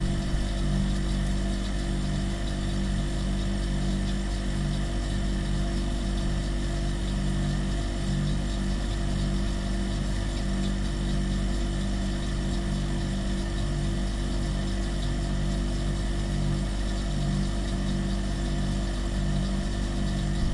水冷却器
描述：办公室水冷却器在工作时的记录。也可以用于冰箱的噪音。